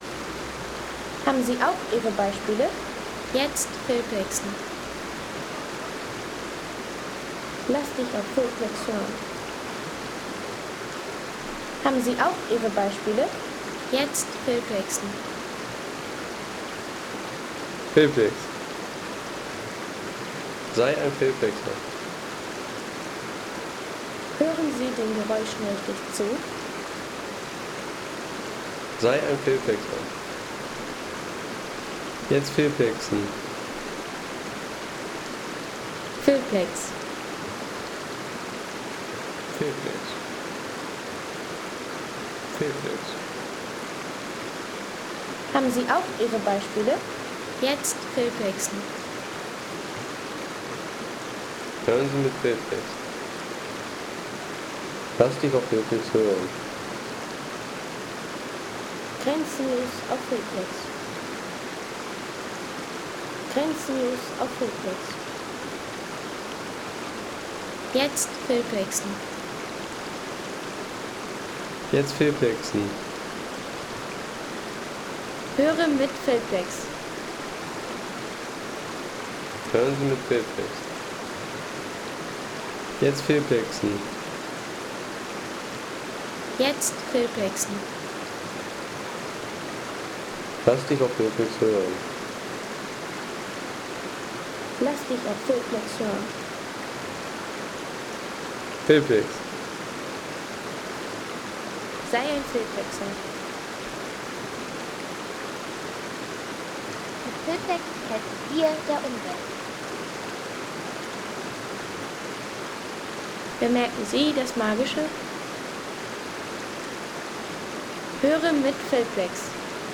Salzafluss – Naturaufnahme vom smaragdgrünen Gebirgsfluss
Ruhiger Salzafluss-Sound aus Österreich mit klarem Gebirgswasser und sanftem Fließen über runde Steine.
Der Salzafluss in Österreich mit sanftem Gebirgswasser, runden Steinen und ruhiger Naturatmosphäre für Film, Postkarten und Hintergrundszenen.